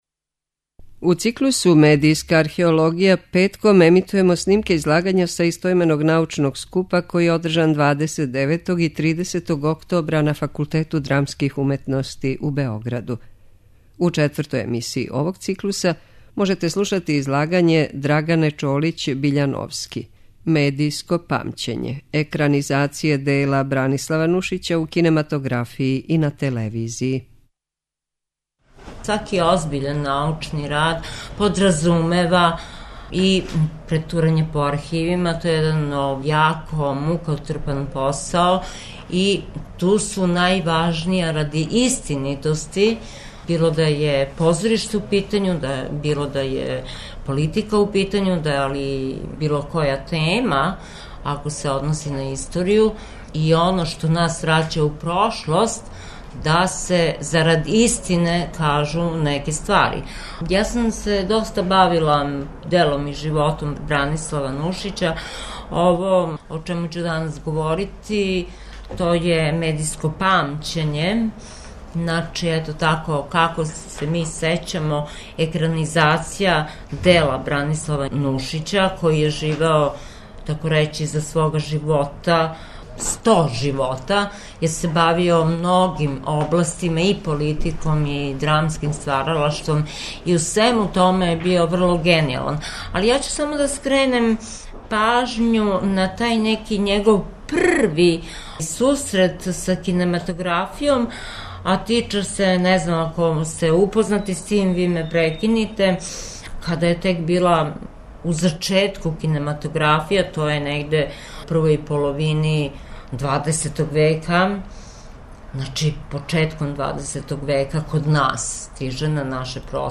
У циклусу МЕДИЈСКА АРХЕОЛОГИЈА петком ћемо емитовати снимке са истоименог научног скупа који је одржан 29. и 30. октобра на Факултету драмских уметности у Београду.
Научни скупови